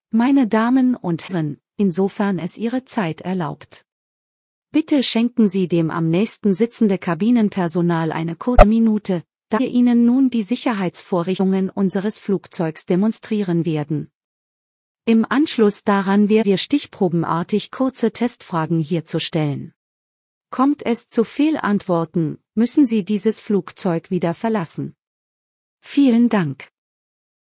PreSafetyBriefing.ogg